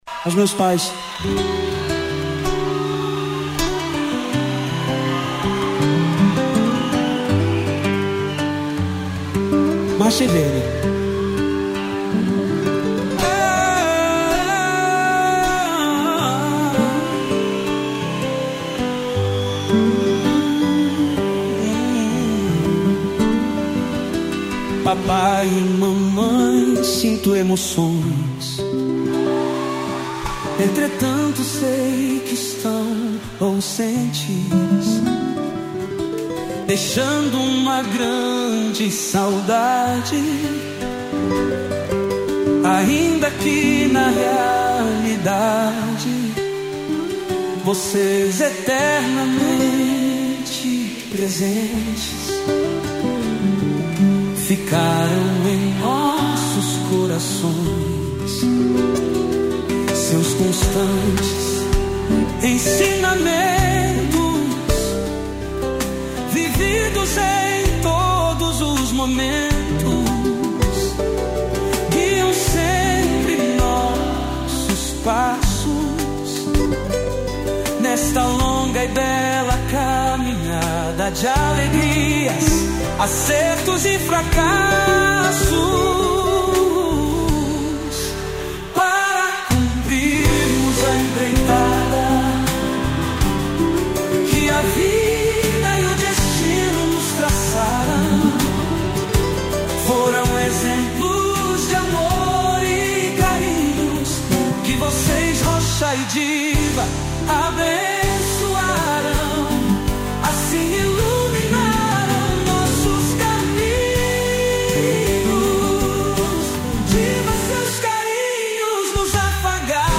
violão